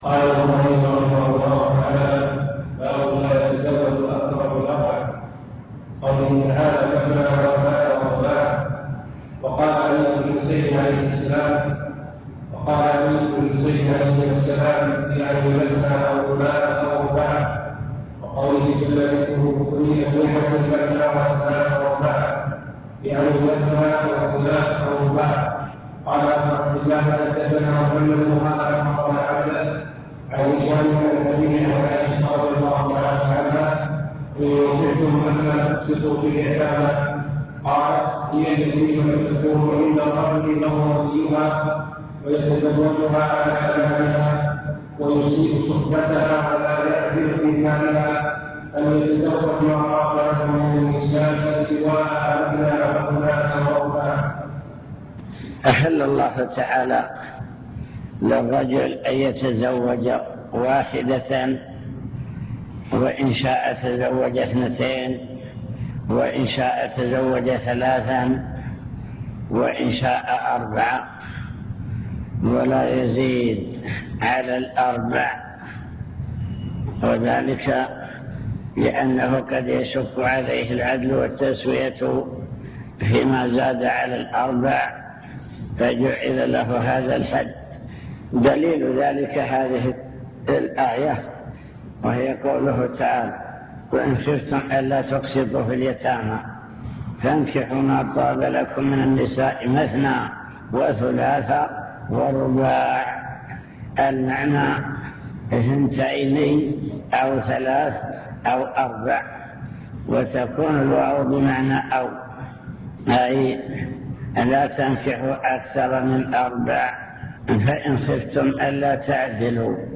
المكتبة الصوتية  تسجيلات - كتب  شرح أحاديث من كتاب النكاح من صحيح البخاري